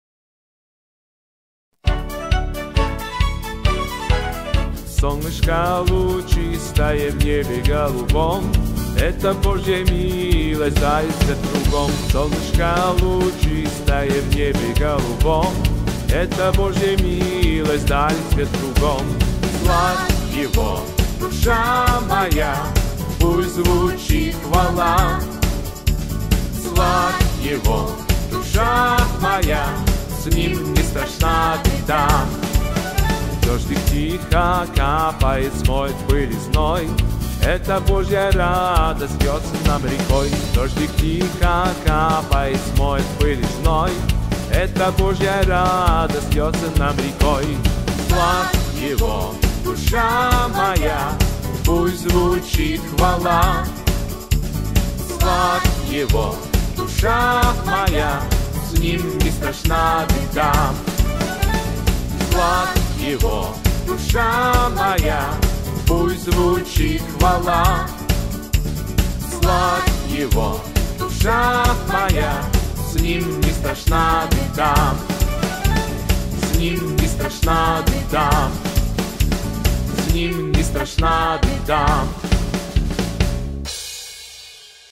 песня
135 просмотров 182 прослушивания 4 скачивания BPM: 132